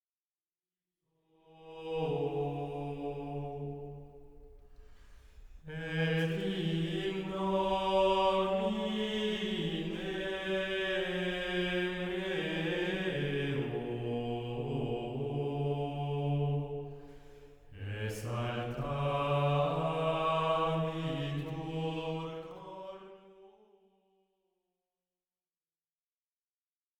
Offertoire